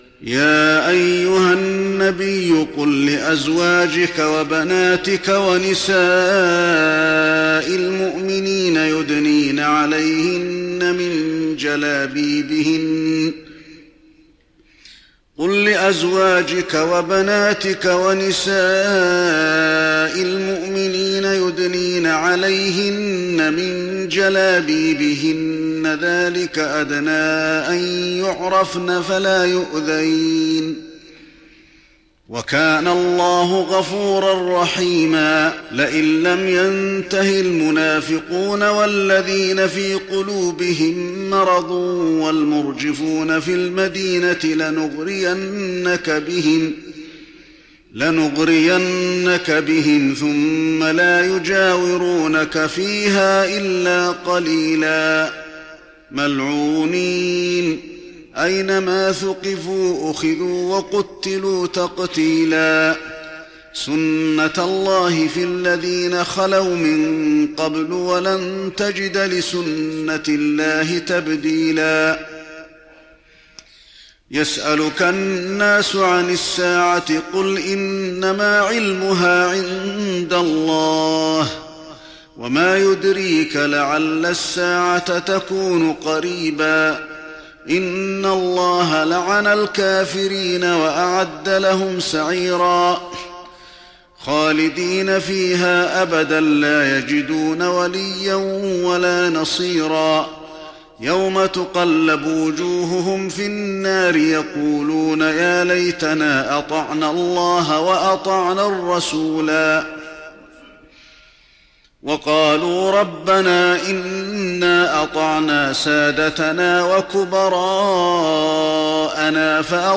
تراويح رمضان 1415هـ من سورة الأحزاب (59-73) وسبأ وفاطر كاملة Taraweeh Ramadan 1415H from Surah Al-Ahzaab to Surah Faatir > تراويح الحرم النبوي عام 1415 🕌 > التراويح - تلاوات الحرمين